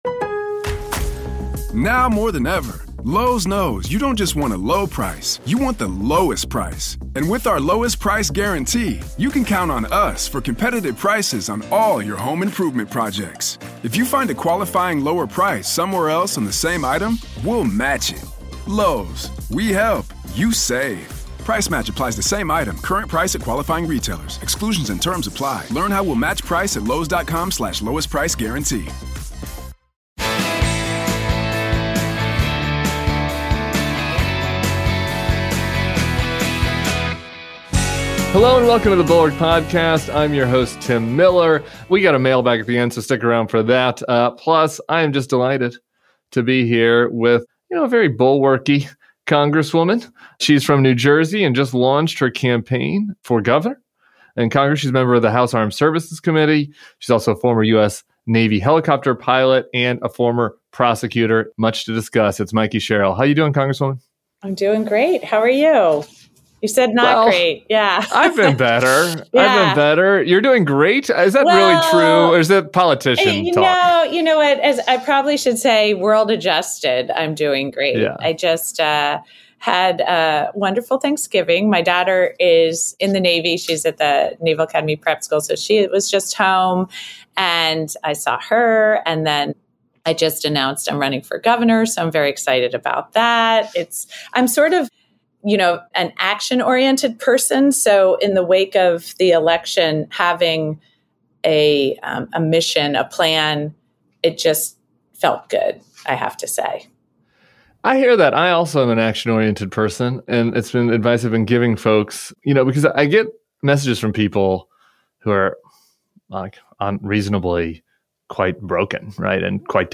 Plus, blue state governors v Trump, the need for more mid-range housing, the role of women in modern combat, and Tim reads more from the mailbag. New Jersey gubernatorial candidate Mikie Sherrill joins Tim Miller.